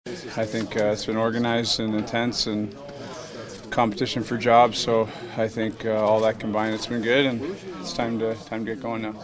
Sidney Crosby says training camp went well and now it’s time to play real hockey.